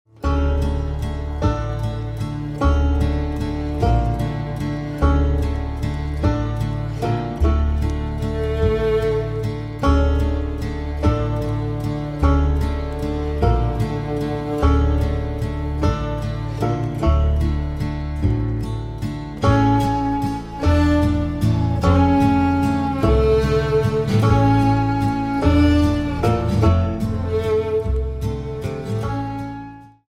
Bluegrass Fantasy/Sci-fi covers